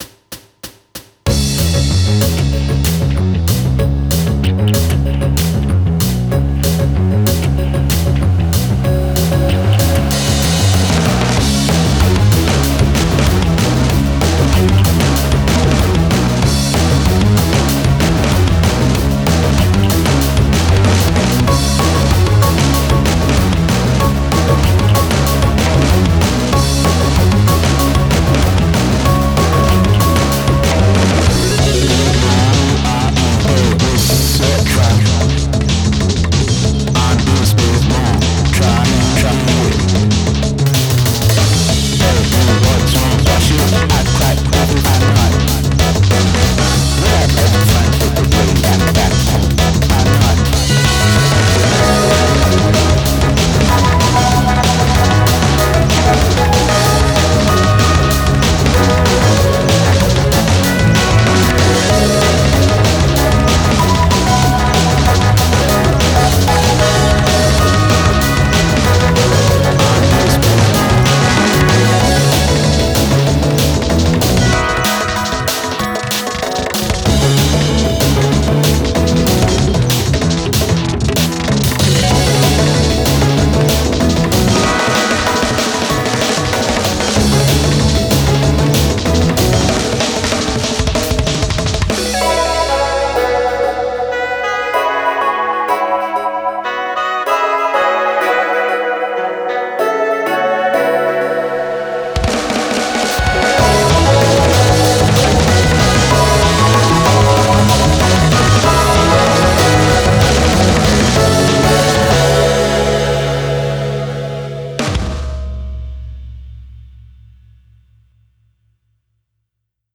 BPM95-190
Audio QualityPerfect (High Quality)
Genre: DRUM'N'BASS